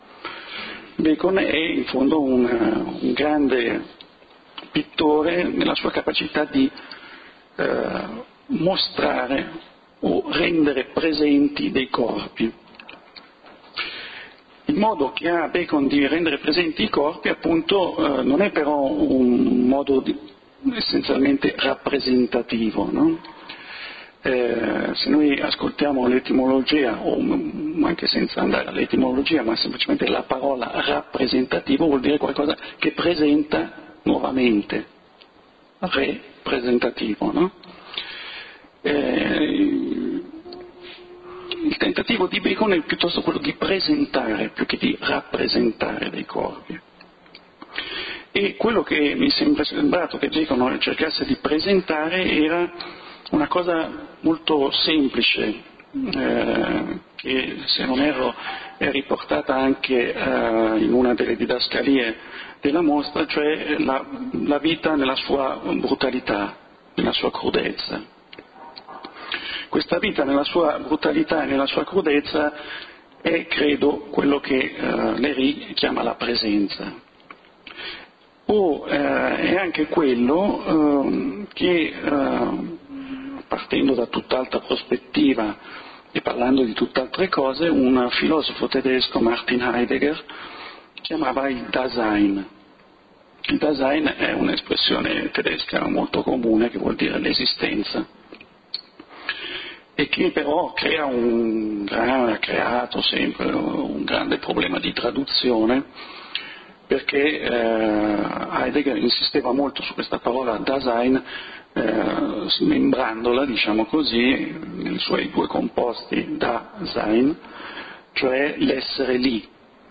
LECTURE / The self-portrait or the vision of time